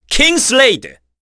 Ezekiel-vox-kingsraid_kr.wav